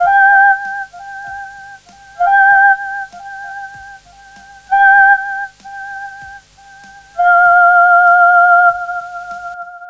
FK097SYNT1-L.wav